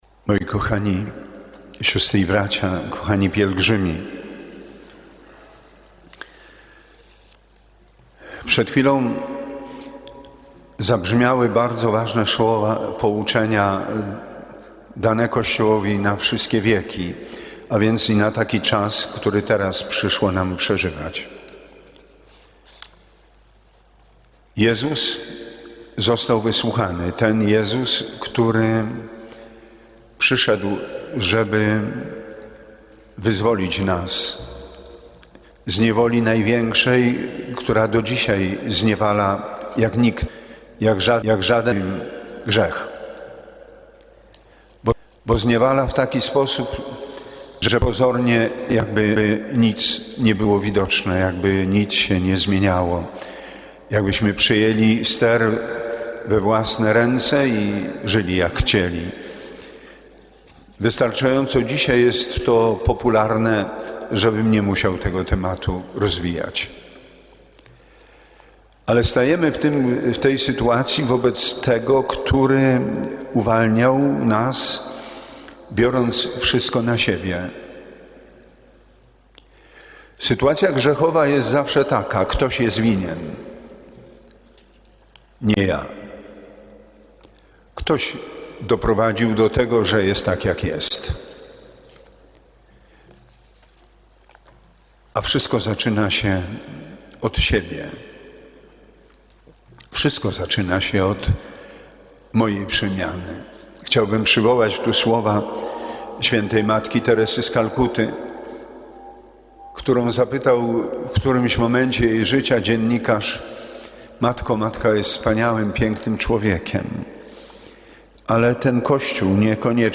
Ze względu na trwającą pandemię, w drogę wyruszyła jedynie delegacja pielgrzymów. Mszy św. na rozpoczęcie wędrówki przewodniczył bp Edward Dajczak.
Posłuchaj homilii bp. Edwarda Dajczaka /files/media/pliki/1.08 bp Dajczak Początek 38.